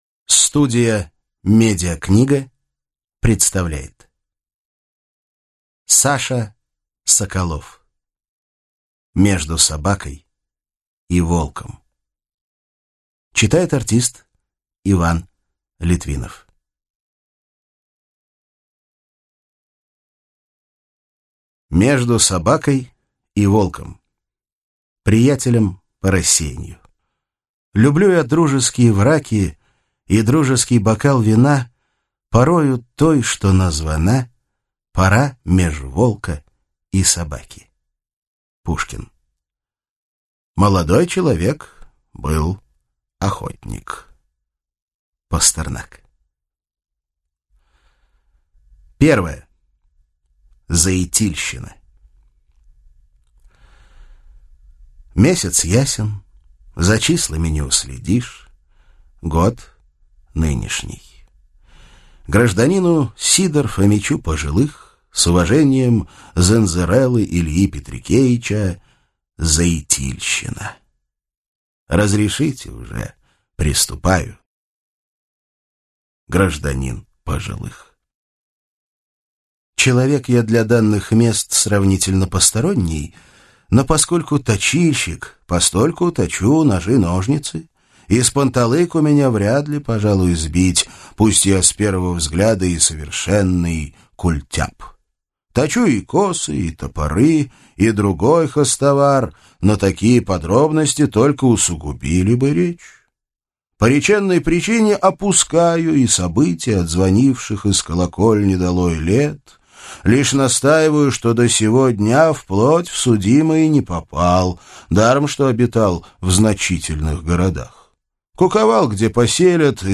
Аудиокнига Между собакой и волком | Библиотека аудиокниг